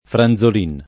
[ fran z ol & n ]